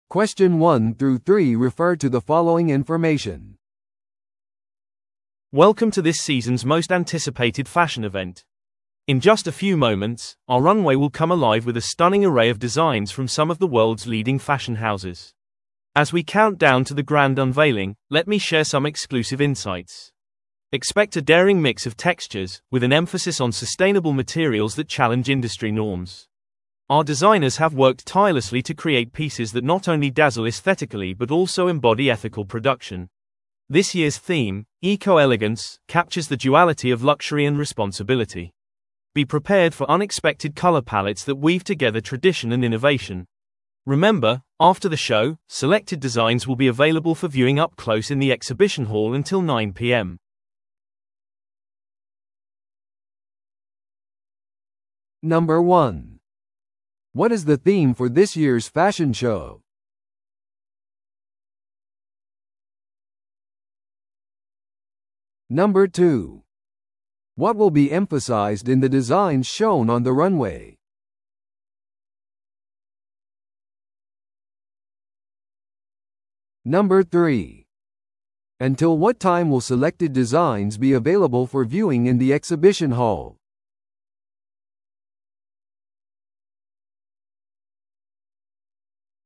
TOEICⓇ対策 Part 4｜ファッションショーの裏側 – 音声付き No.059